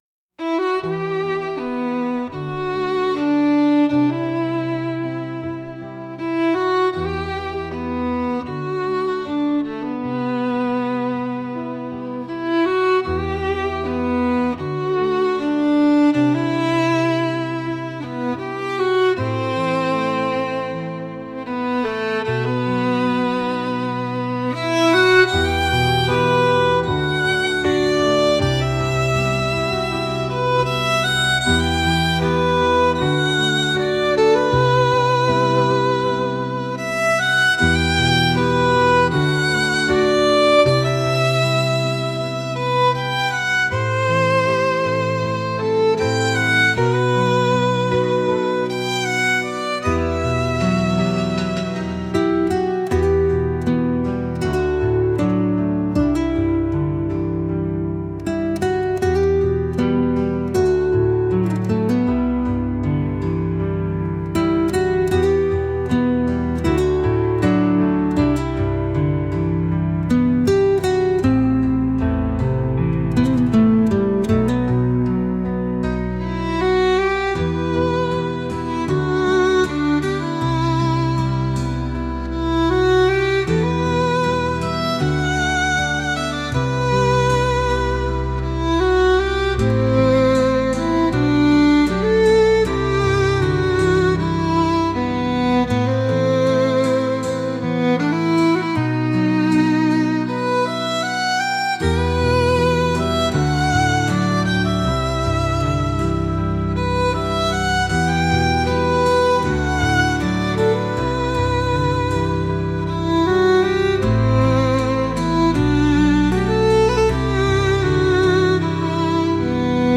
悲しさを感じさせるケルト音楽です。